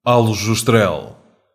Aljustrel (Portuguese pronunciation: [alʒuʃˈtɾɛl]
Pt-pt_Aljustrel_FF.ogg.mp3